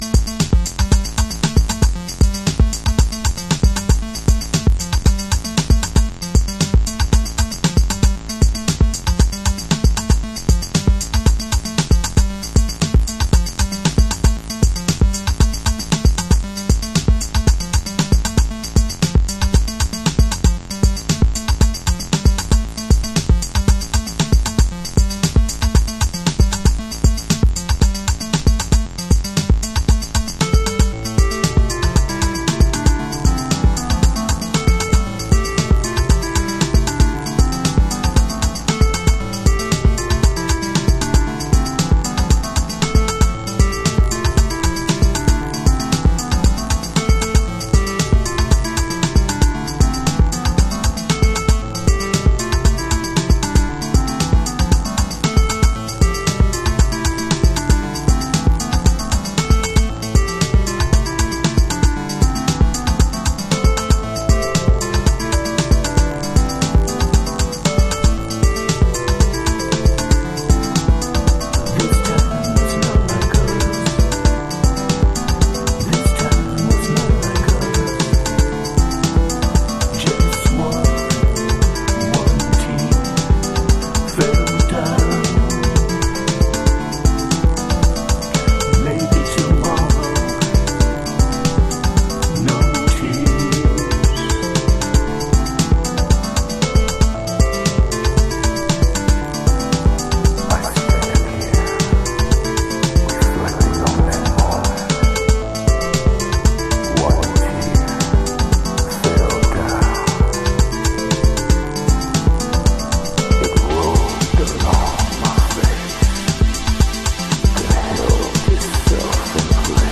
Chicago Oldschool / CDH
Back to the 80'sなビート。
Club Mix